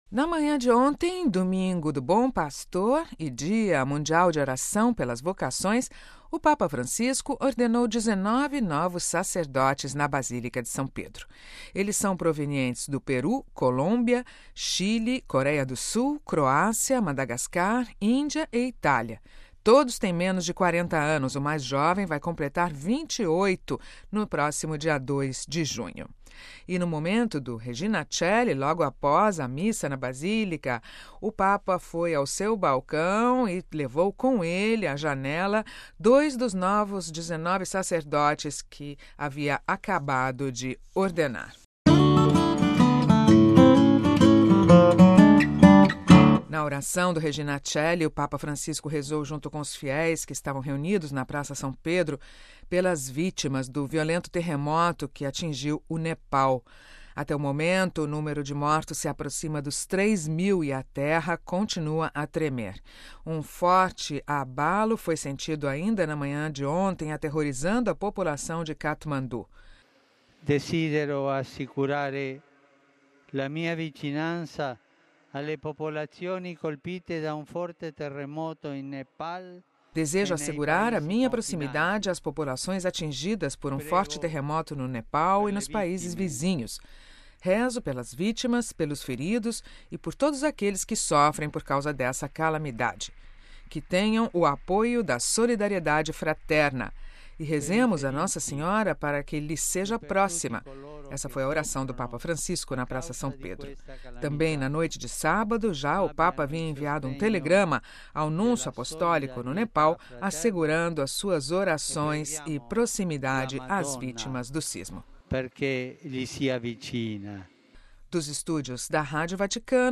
Boletim da Rádio Vaticano